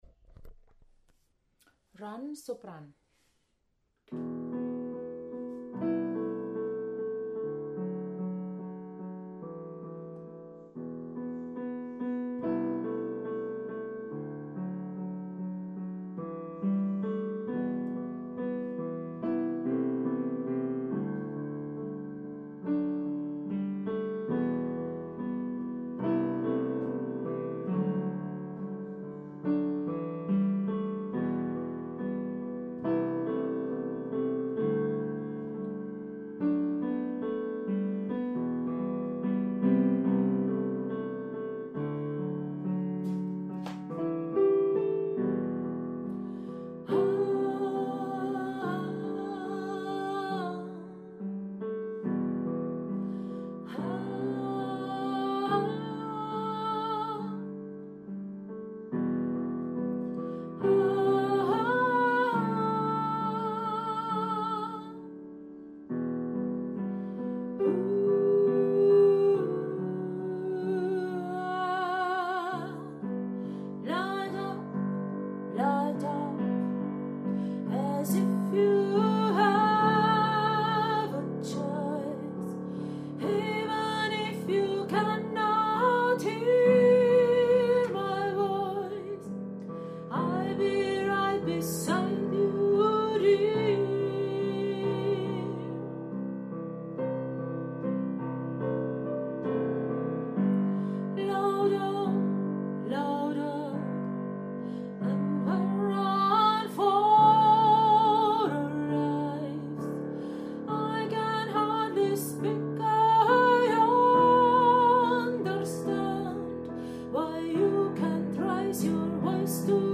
Run-Sopran.mp3